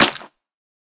Minecraft Version Minecraft Version snapshot Latest Release | Latest Snapshot snapshot / assets / minecraft / sounds / block / muddy_mangrove_roots / step5.ogg Compare With Compare With Latest Release | Latest Snapshot
step5.ogg